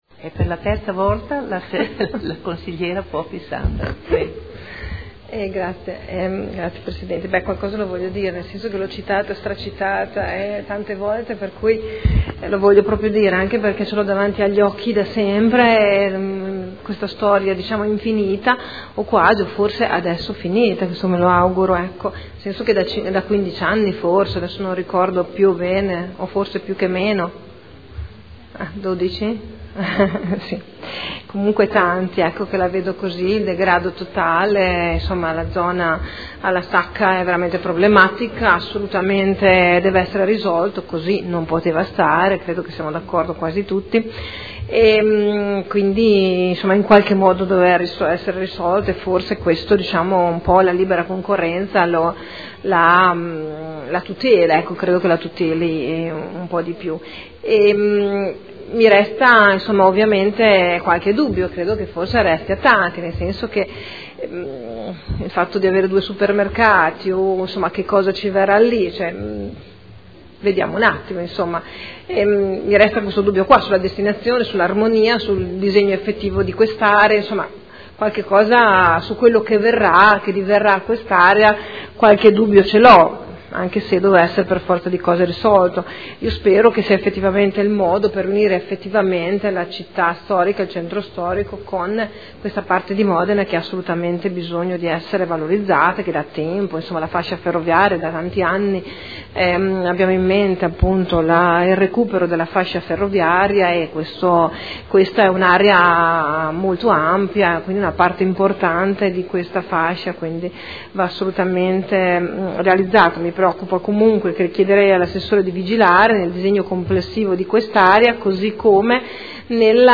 Sandra Poppi — Sito Audio Consiglio Comunale